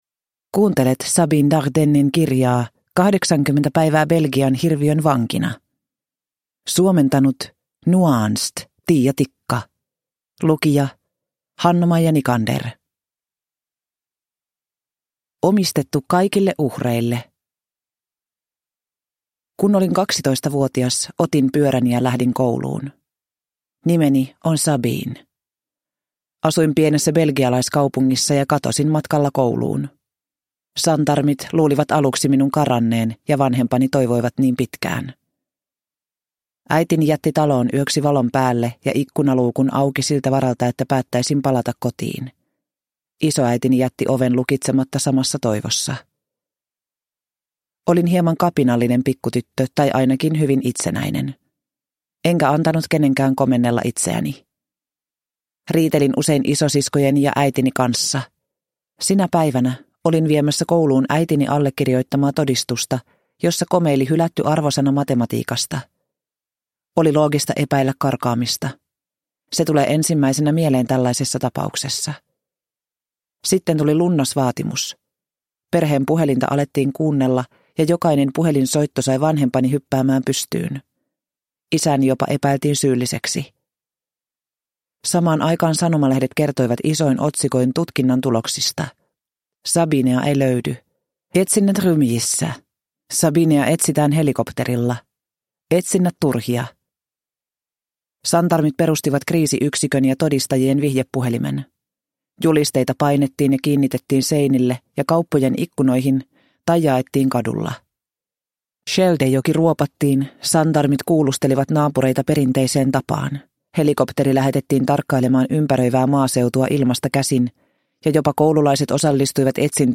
80 päivää Belgian hirviön vankina – Ljudbok